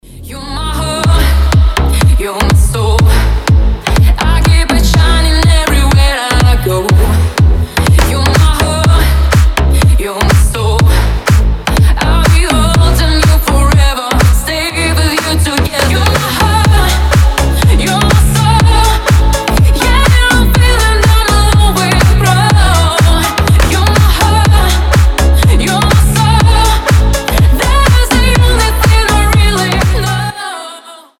• Качество: 320, Stereo
громкие
deep house
женский голос
Club House
slap house